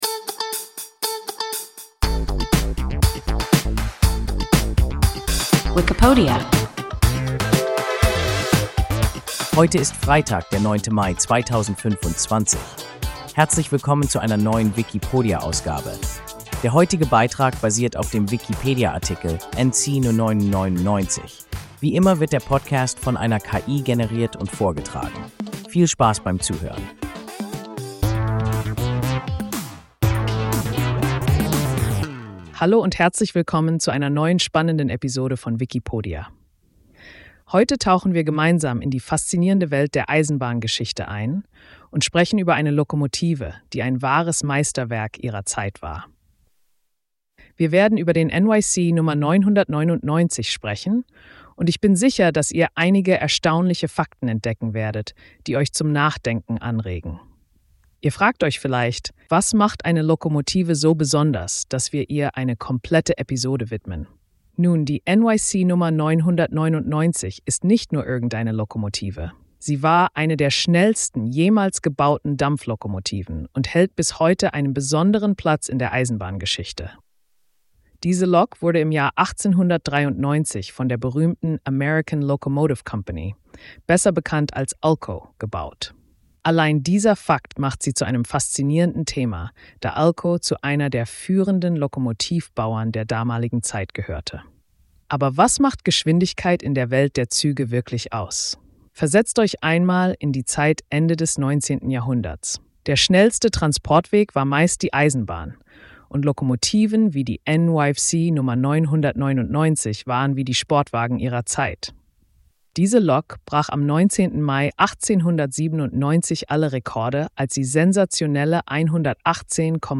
NYC Nr. 999 – WIKIPODIA – ein KI Podcast